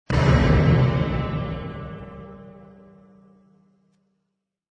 Descarga de Sonidos mp3 Gratis: golpe 8.